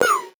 Index of /musicradar/8-bit-bonanza-samples/VocoBit Hits
CS_VocoBitC_Hit-03.wav